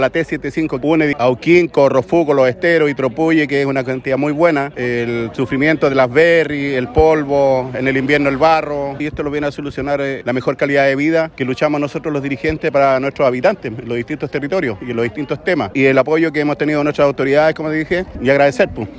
La ceremonia se desarrolló este miércoles con la presencia de autoridades regionales y locales.